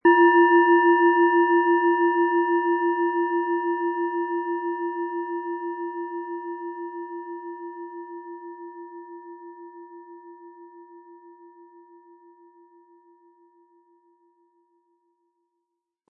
Ihr Klang bleibt dabei klar, harmonisch und lebendig – getragen von all den Erfahrungen, die in dieser Schale mitschwingen.
Ein die Schale gut klingend lassender Schlegel liegt kostenfrei bei, er lässt die Planetenklangschale Platonisches Jahr harmonisch und angenehm ertönen.
PlanetentonPlatonisches Jahr
MaterialBronze